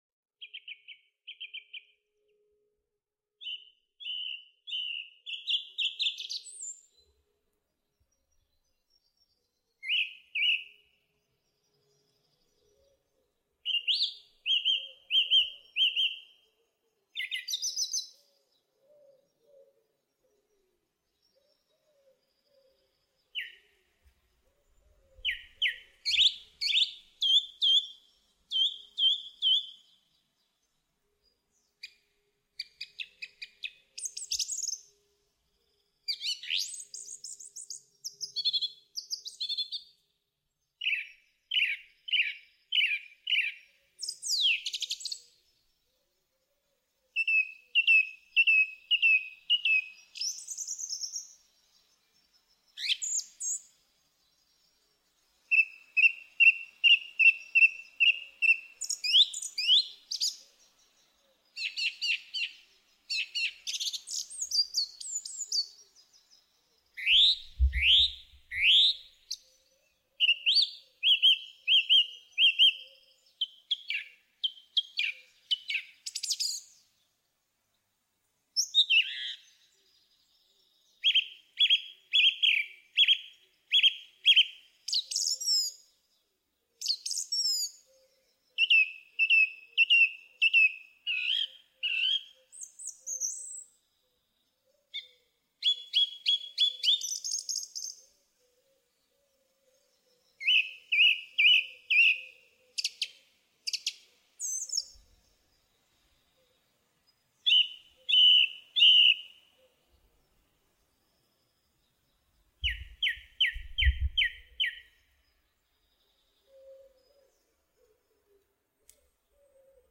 song thrush
A song thrush singing outside of our hallway window....